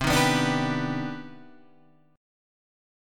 CmM9 chord {8 6 9 7 8 7} chord